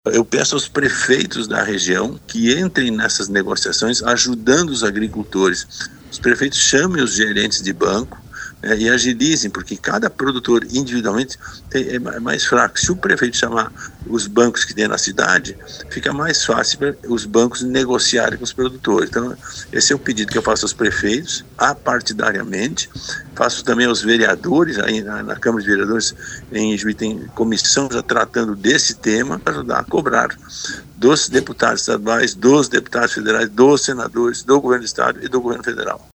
No entanto, ao falar com a RPI nesta manhã, o parlamentar reforçou a importância de aprovar o projeto de securitização, que ele próprio apresentou no Senado. A matéria estabelece renegociação de débitos rurais por 20 anos.